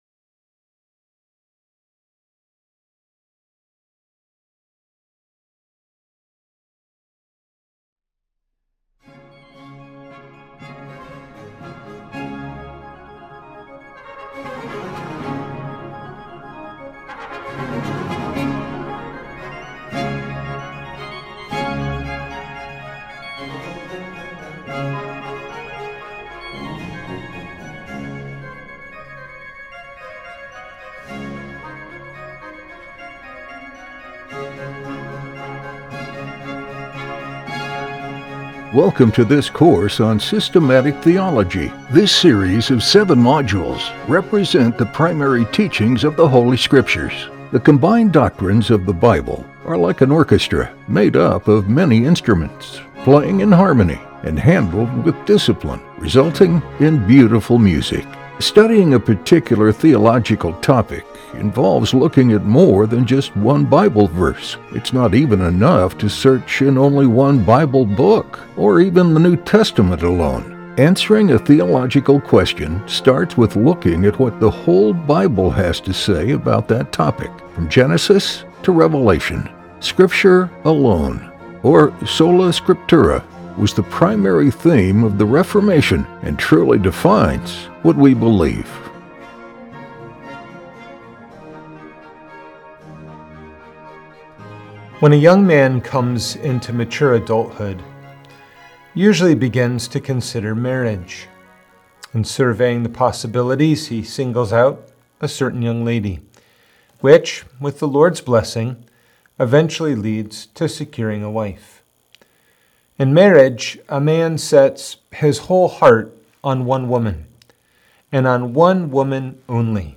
In this lecture, we will explore the Extent of the Atonement, which addresses the question, For whom did Christ die?